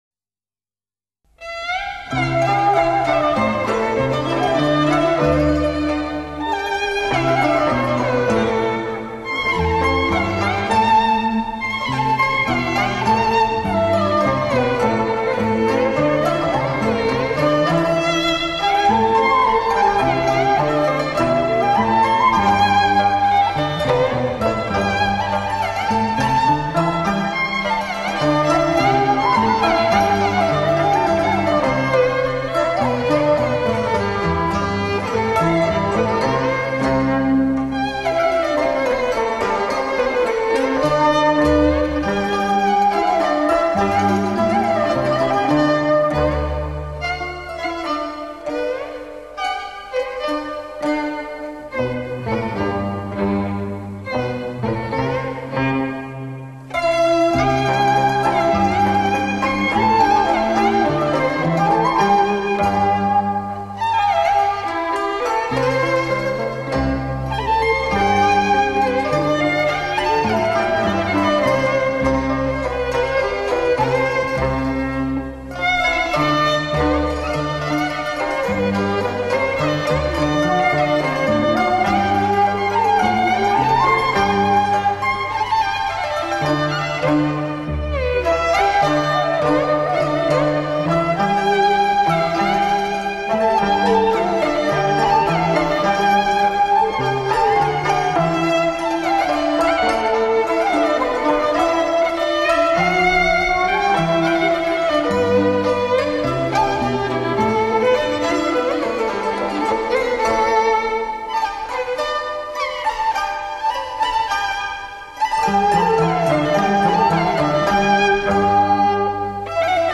小提琴奏广东音乐 洋为中用
音域宽且表现力强 别有韵味
广东音乐用小提琴演奏，另有韵味。